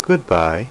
Goodbye Sound Effect
goodbye.mp3